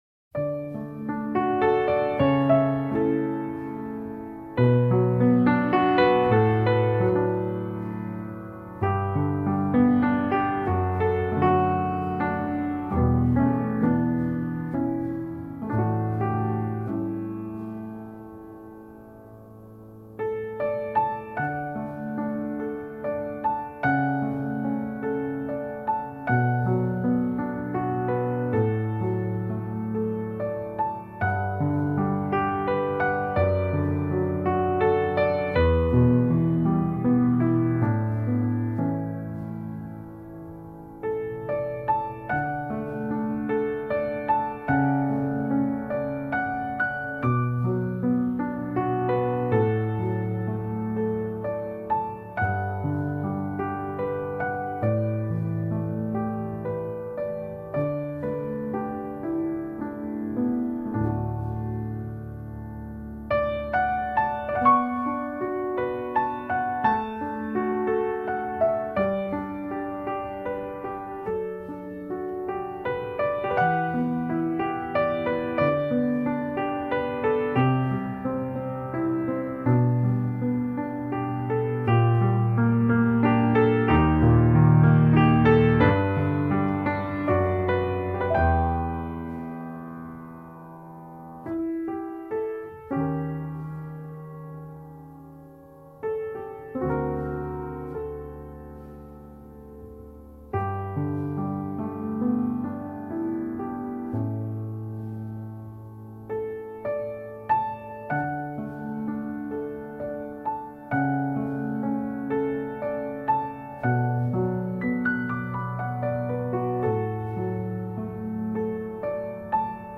Tagged as: New Age, Classical, New Age Piano